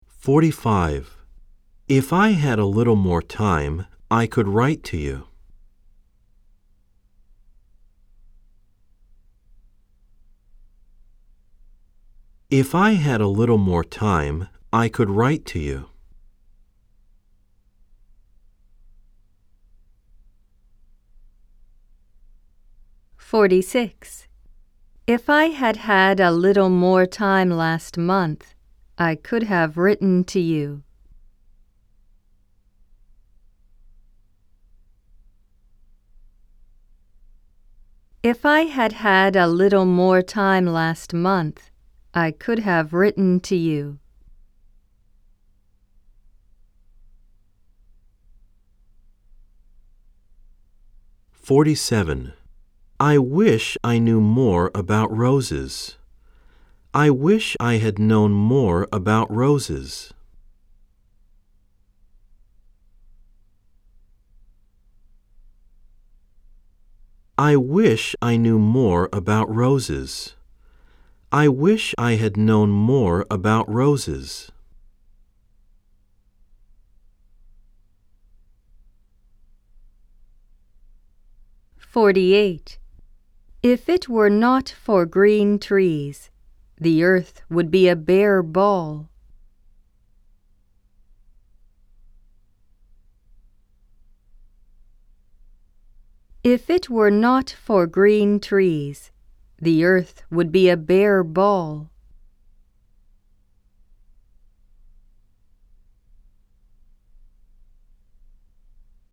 （3）暗唱例文100　各章別ファイル（英文2回読み）
※（1）（2）では英文のあとに各5秒のポーズ、（3）（4）では各7秒のポーズが入っています。